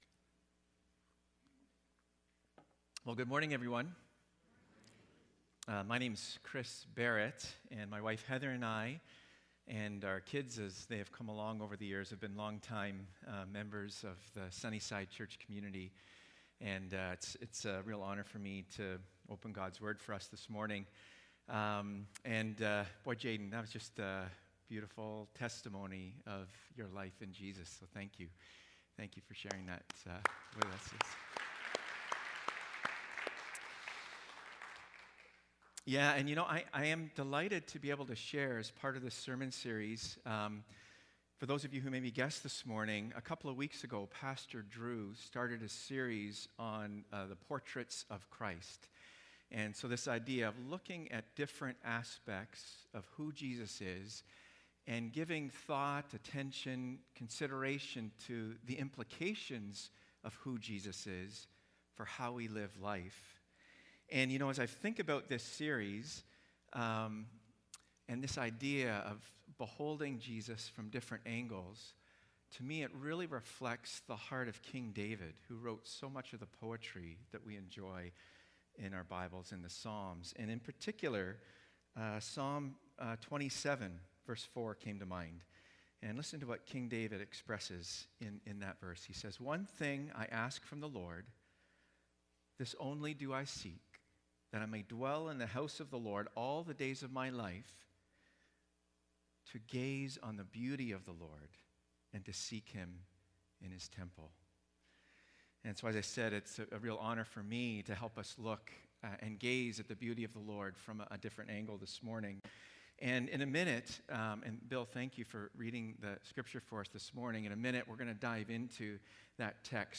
Sermons | Sunnyside Wesleyan Church